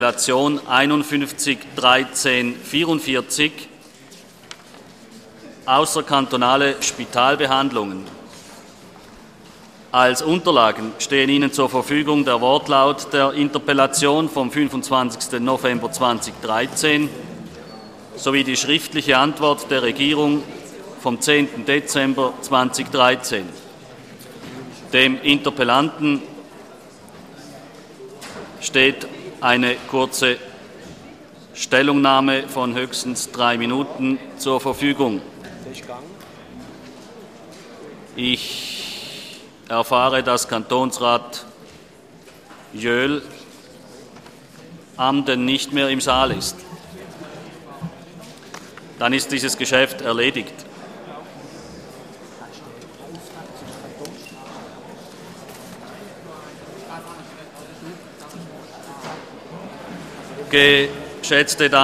26.2.2014Wortmeldung
Session des Kantonsrates vom 26. Februar 2014, ausserordentliche Session